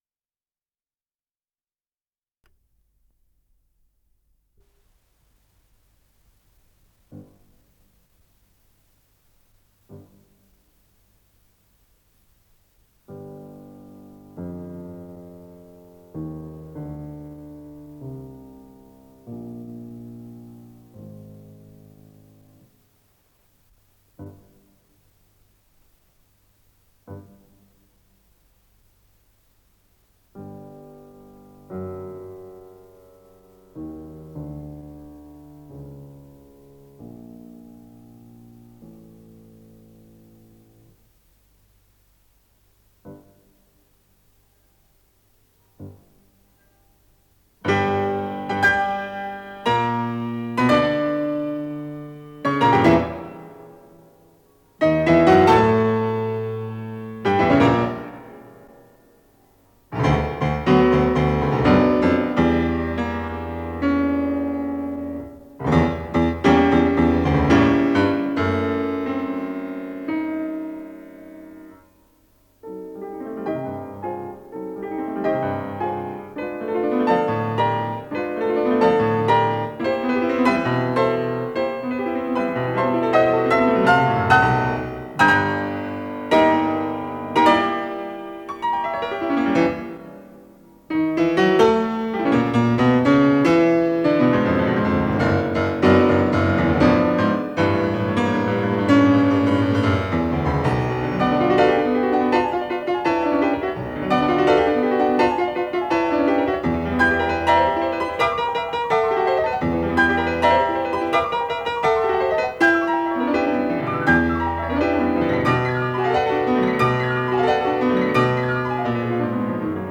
Исполнитель: Артур Морейра-Лима - фортепиано
Соната
Си минор